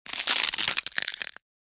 findbreak.wav